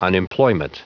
Prononciation du mot unemployment en anglais (fichier audio)
Prononciation du mot : unemployment